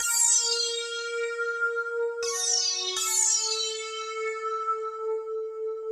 01F-SYN-.A-R.wav